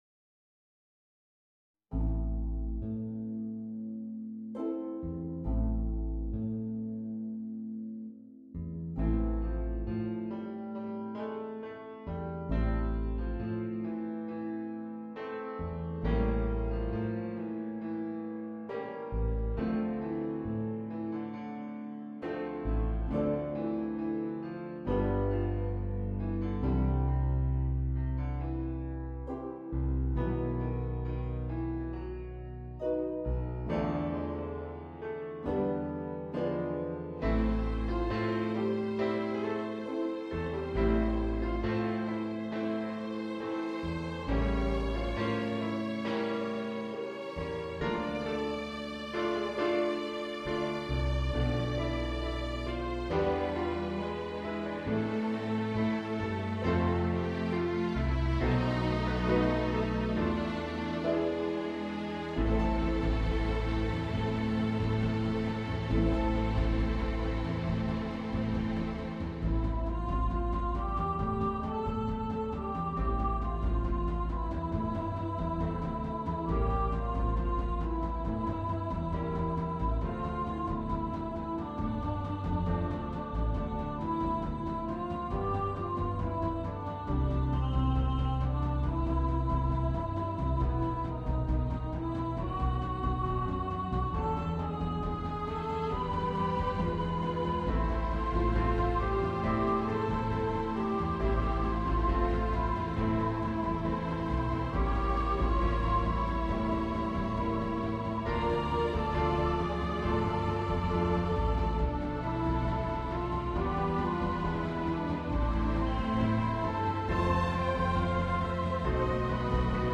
на симфонический оркестр.